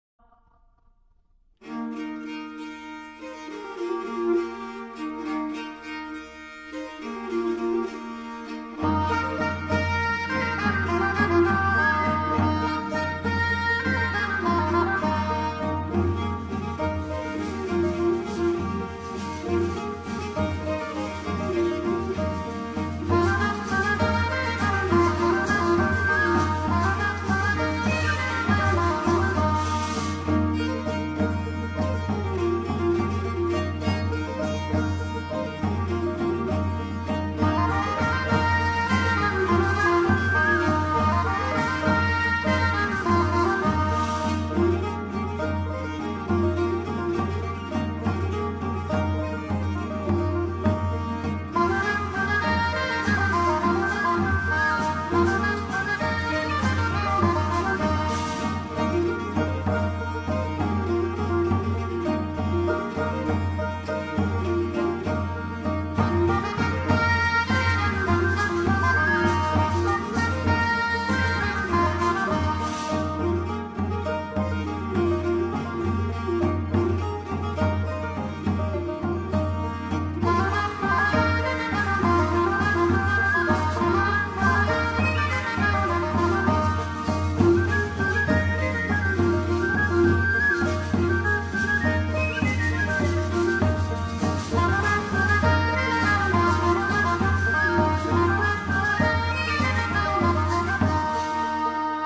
Medieval-Italian-Music-Segment.mp3